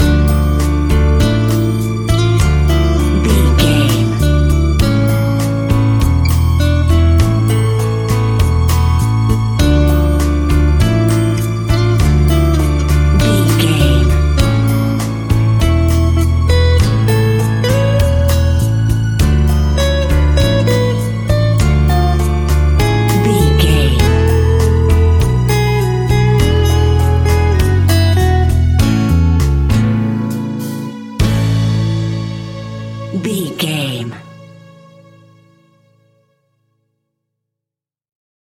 An exotic and colorful piece of Espanic and Latin music.
Ionian/Major
Slow
romantic
maracas
percussion spanish guitar